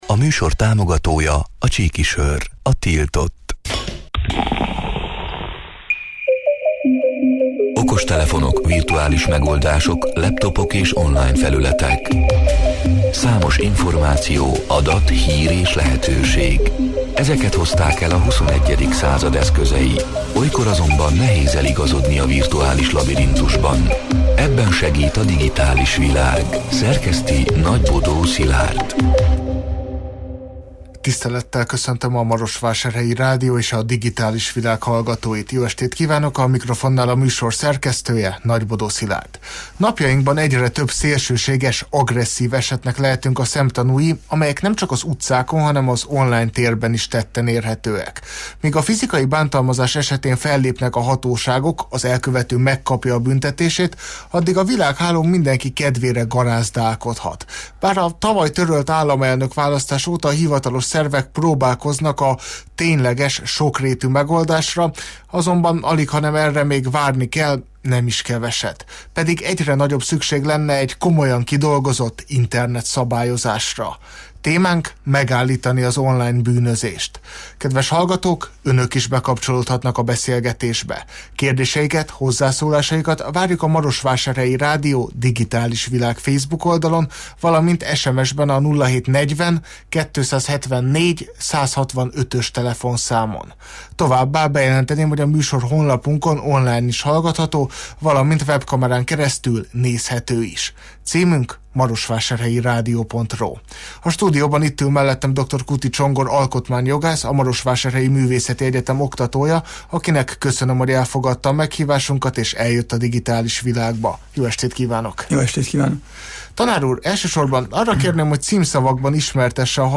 A Marosvásárhelyi Rádió Digitális Világ (elhangzott: 2025. április 8-án, kedden este nyolc órától élőben) c. műsorának hanganyaga: